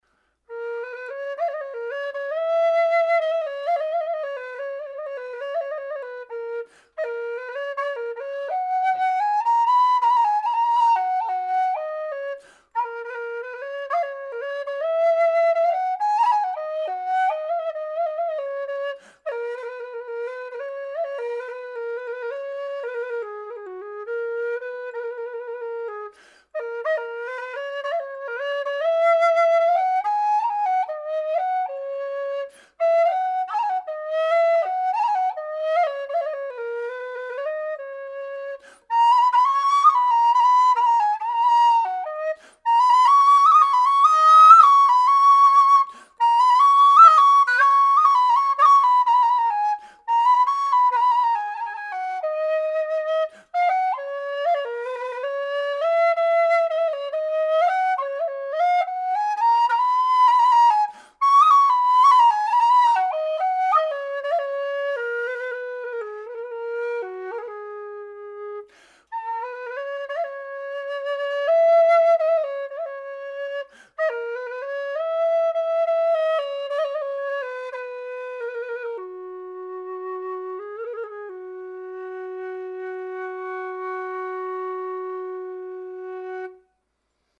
Low Gb/F# Whistles
low Gb/F# whistle - 165 GBP
made out of thin-walled aluminium tubing with 18mm bore
LowFsh-impro2.mp3